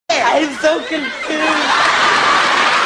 (The voice is John Travolta in 1979 as Vinnie Barbarino on “Welcome Back Kotter”.)
im-so-confused.wav